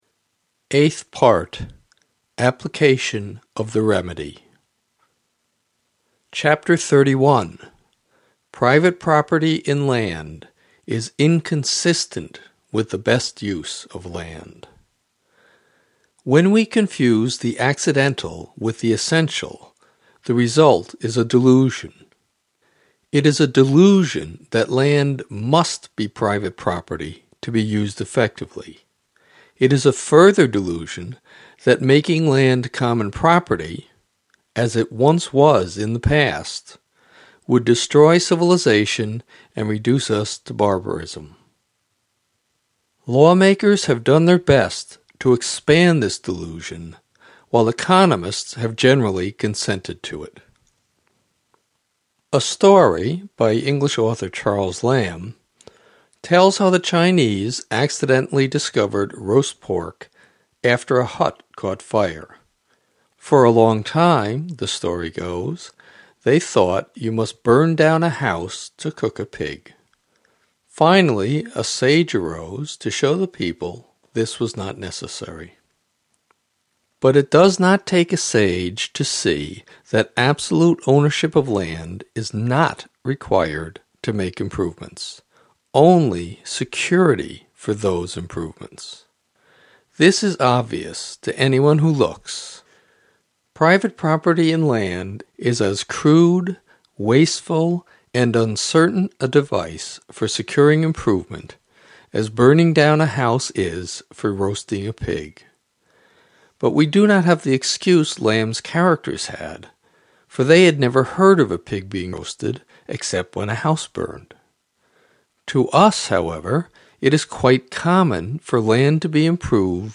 Audiobook of Progress and Poverty
This audiobook is provided free by the Henry George School of Chicago.